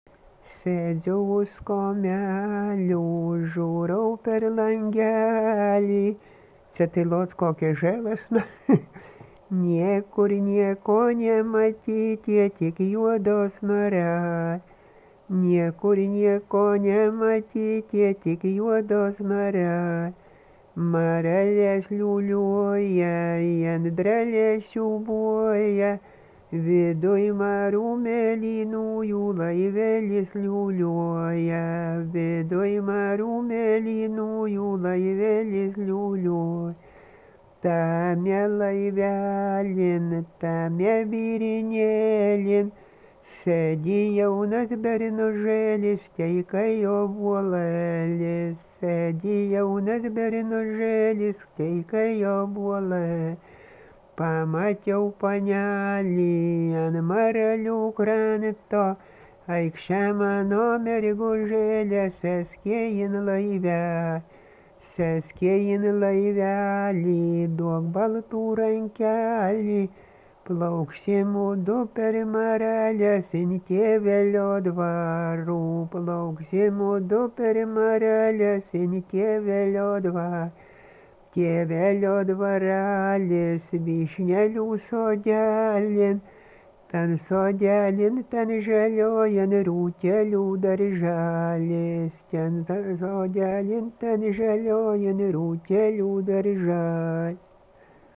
Dalykas, tema daina
Erdvinė aprėptis Valančiūnai
Atlikimo pubūdis vokalinis